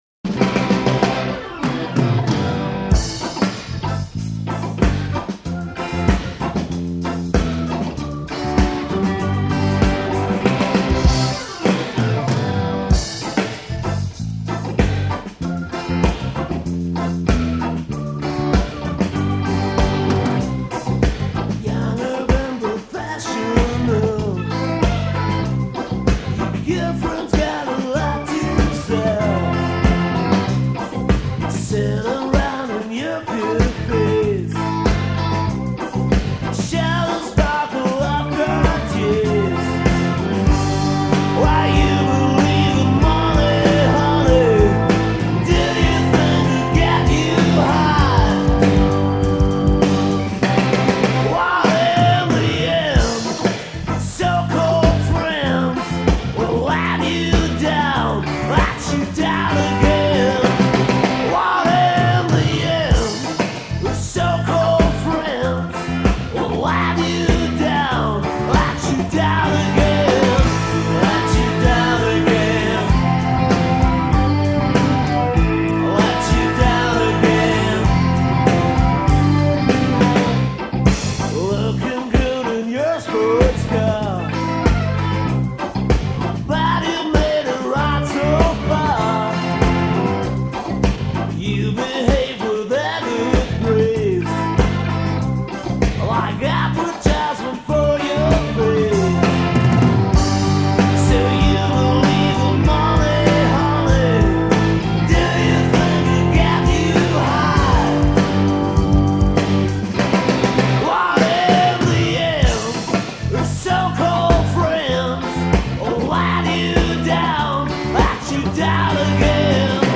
Bass & Voc.
Drums
Key’s & Piano